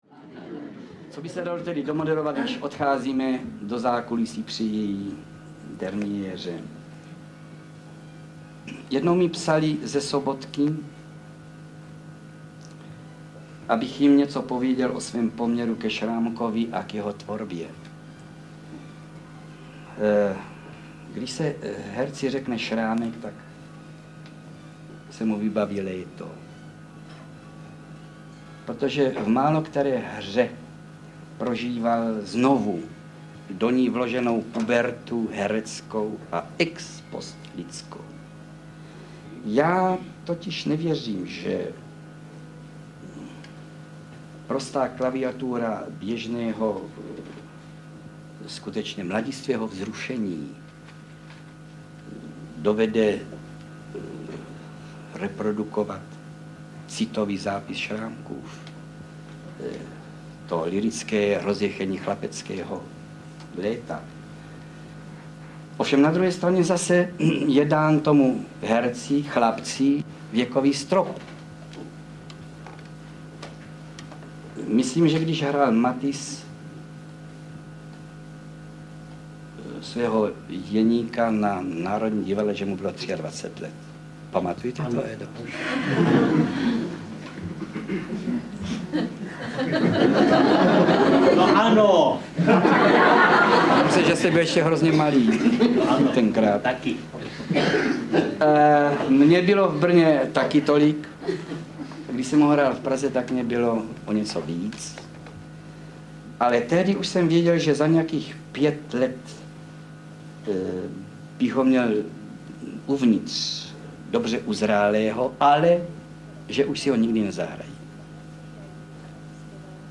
Vzpomínka na Karla Högera audiokniha
Ukázka z knihy
• InterpretKarel Höger, Miroslav Masopust, Miloš Nesvadba, Jana Preissová, Libuše Švormová, František Vicena, Václav Voska, Eduard Cupák, Vlasta Fabianová, František Filipovský, Josef Bek